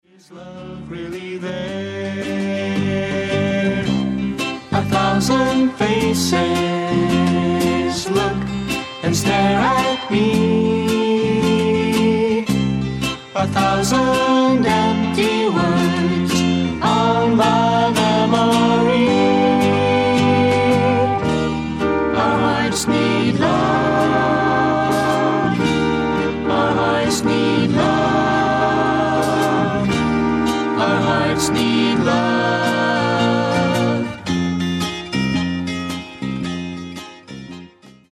SOFT ROCK / GARAGE